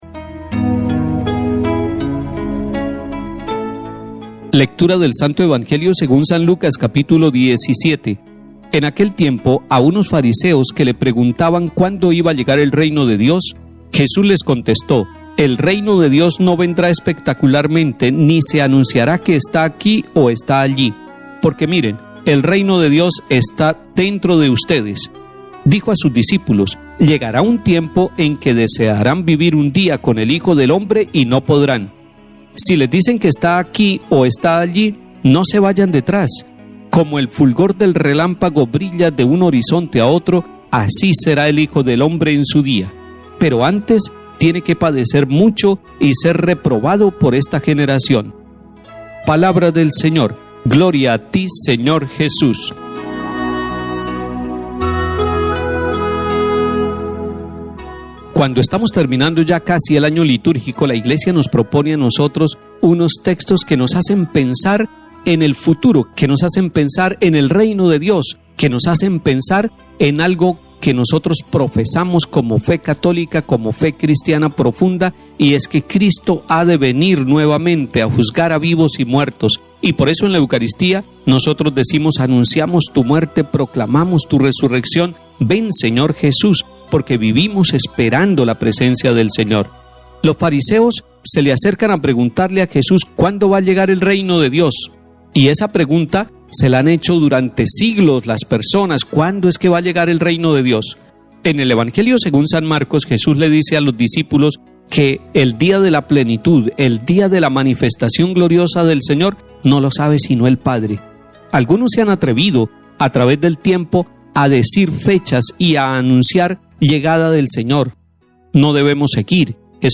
Comentario del cardenal Mons. Luis José Rueda Aparicio, Arzobispo de Bogotá y Primado de Colombia.